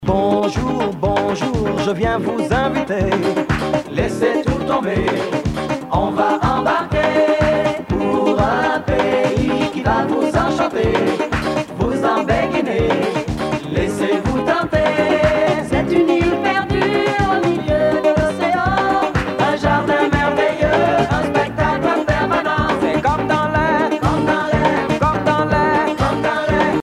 danse : biguine
Pièce musicale éditée